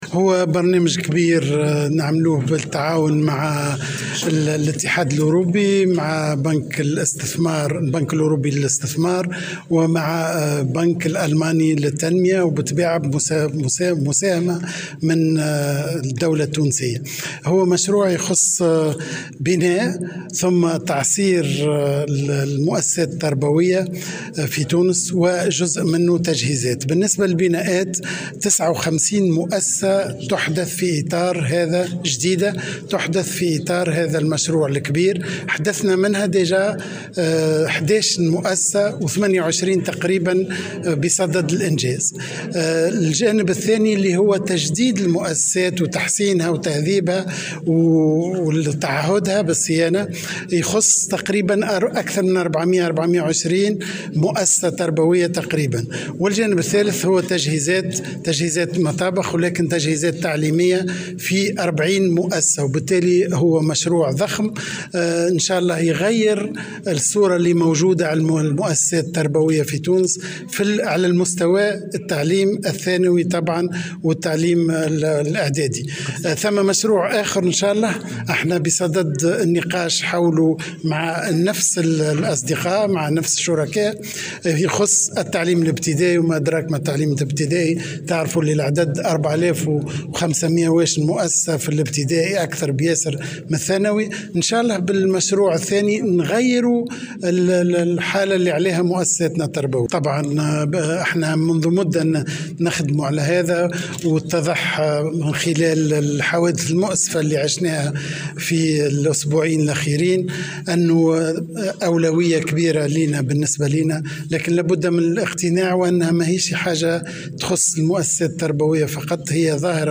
قال وزير التربية فتحي السلاوتي، في تصريح لمراسل الجوهرة اف ام، اليوم الاربعاء إن...
وأكد وزير التربية، لدى إشرافه على افتتاح مدرسة اعدادية بالمرناقية، أنه قد تم التعهد باصلاح 420 مؤسسة تربوية وتوفير تجهيزات للمطابخ ومعدات تعليمية بـ40 مؤسسة.